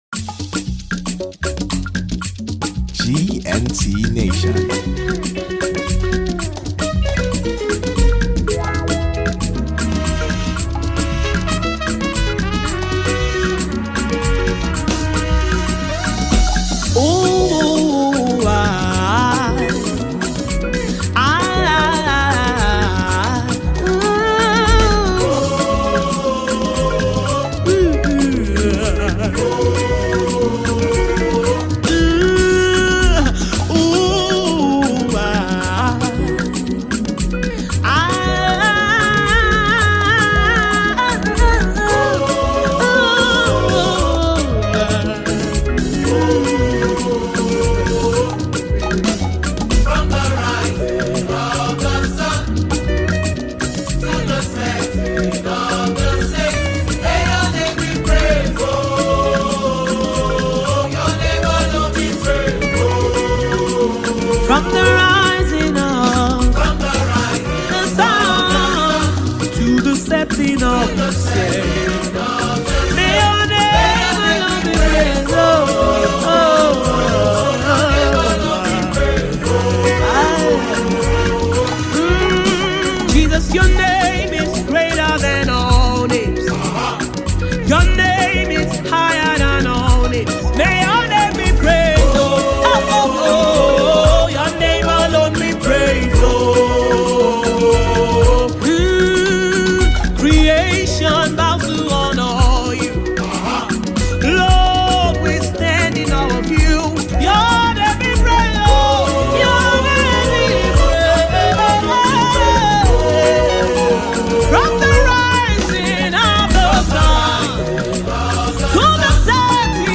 GOSPEL SONGS
is a powerful worship anthem
contemporary gospel sounds with traditional worship elements